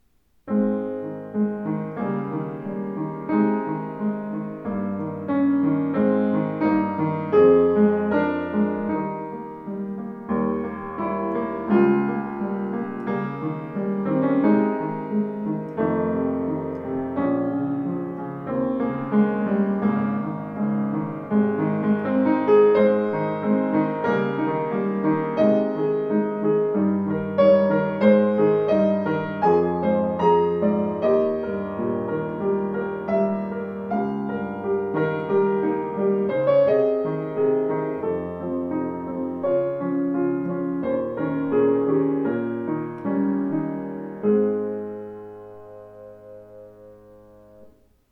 Dämpfungspedal (Moderator), großes Tonvolumen dank neuer Konstruktion mit großzügig ausgelegtem Resonanzboden aus ausgesuchter Bergfichte.
Klaviere